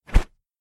На этой странице собраны разнообразные звуки мачете: от резких ударов до плавного разрезания.
Звук замаха, когда в руке мачете (перед ударом) (00:01)